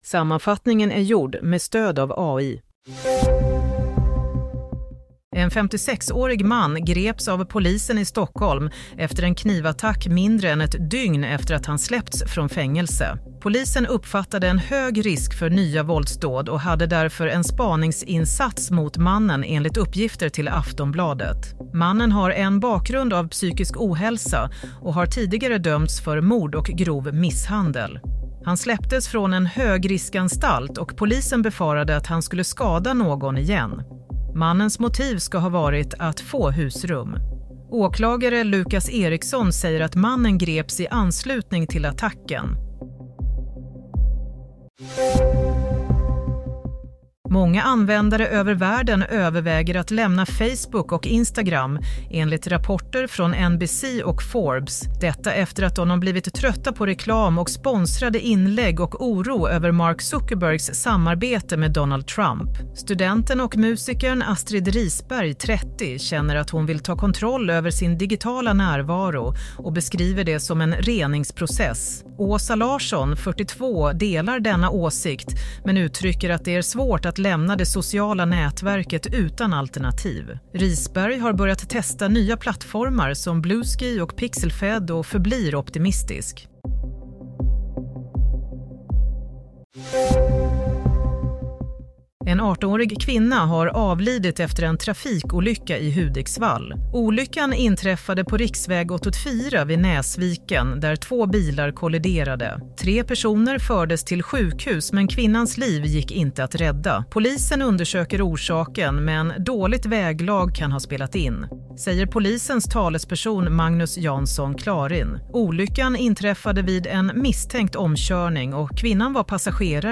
Nyhetssammanfattning - 24 januari 22.00
Sammanfattningen av följande nyheter är gjord med stöd av AI.